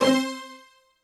STR HIT C4 2.wav